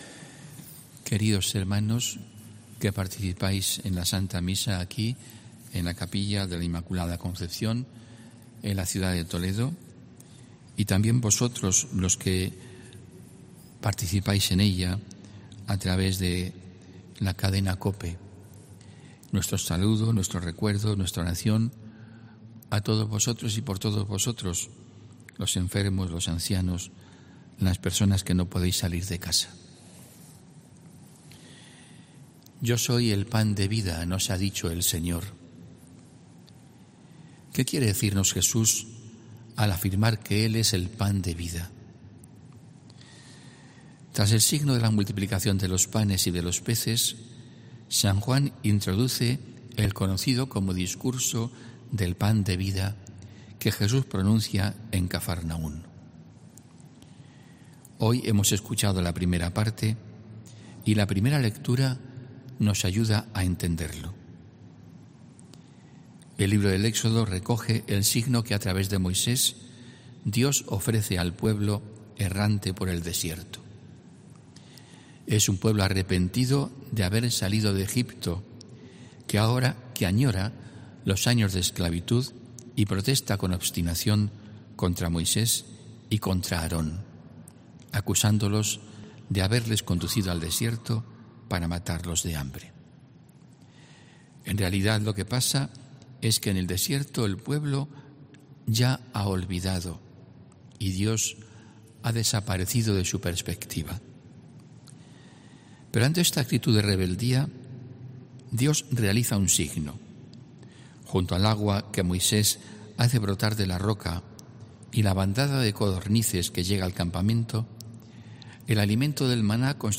HOMILÍA 1 AGOSTO 2021